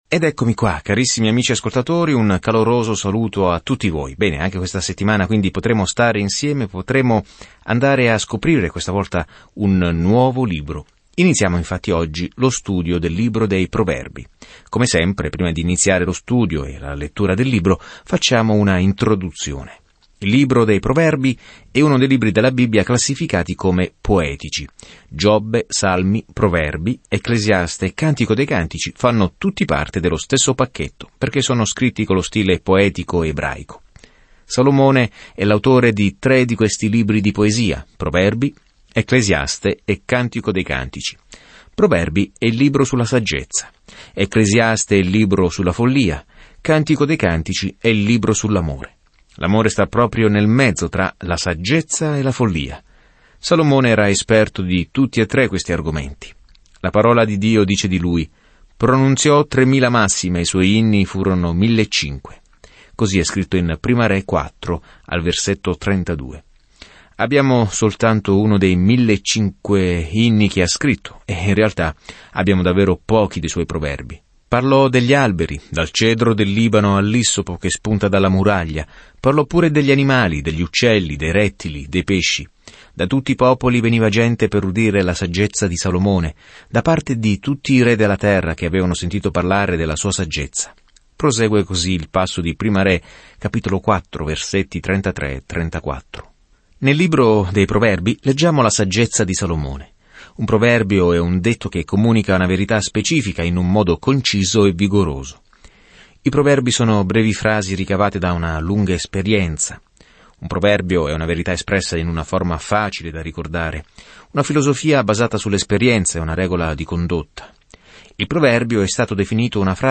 Scrittura Proverbi 1:4 Inizia questo Piano Giorno 2 Riguardo questo Piano I proverbi sono brevi frasi tratte da lunghe esperienze che insegnano la verità in un modo facile da ricordare: verità che ci aiutano a prendere decisioni sagge. Viaggia ogni giorno attraverso i Proverbi mentre ascolti lo studio audio e leggi versetti selezionati della parola di Dio.